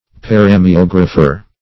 Search Result for " paramiographer" : The Collaborative International Dictionary of English v.0.48: Paramiographer \Par`a*mi*og"ra*pher\, n. [Gr.